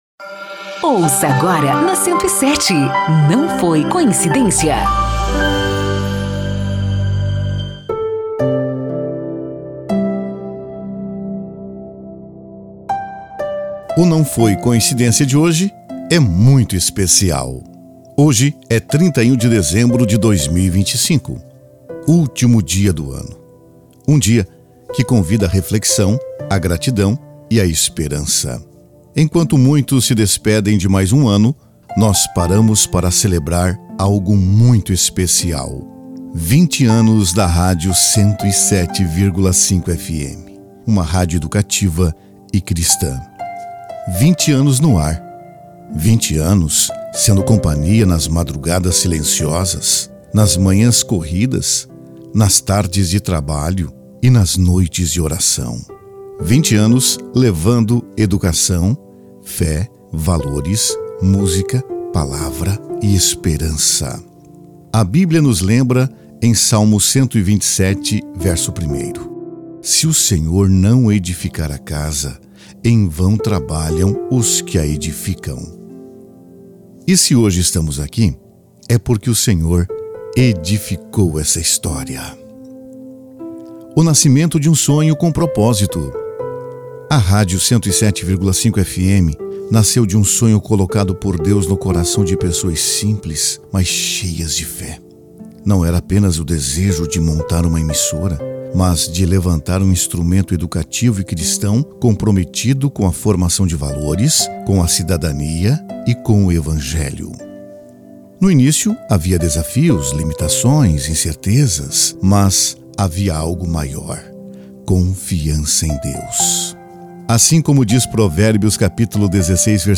Acompanhe esta reflexão narrada